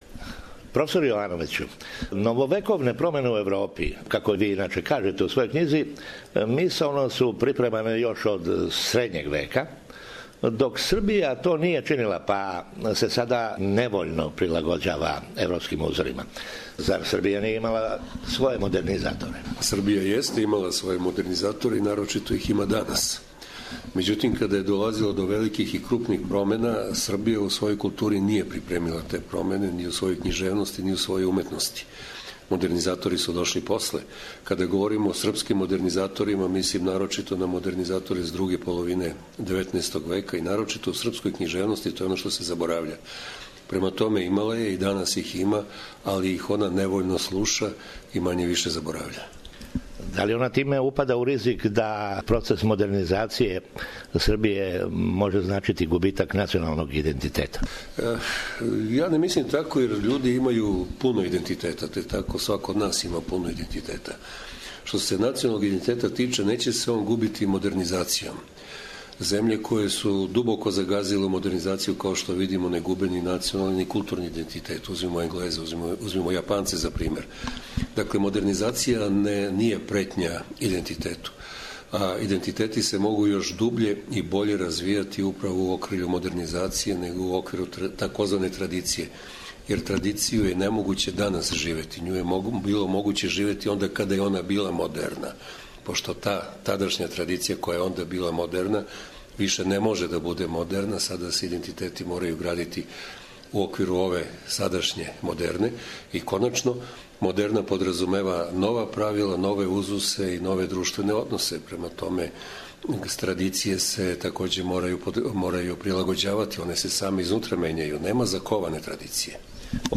интервјуу